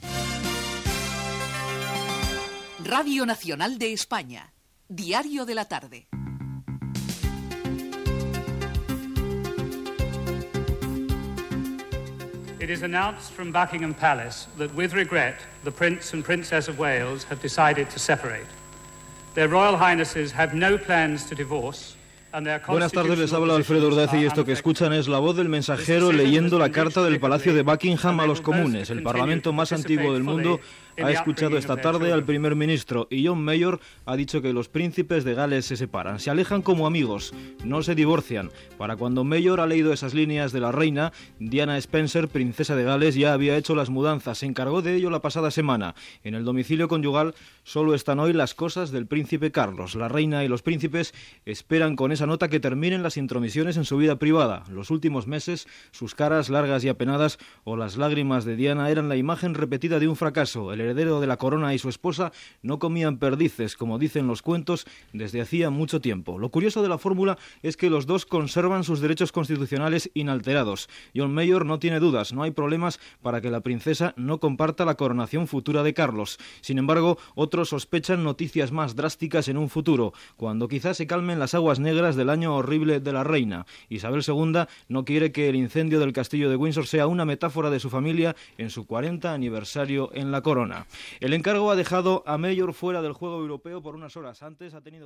Indicatiu del programa, els Prínceps de Gal·les anuncien la seva separació
Informatiu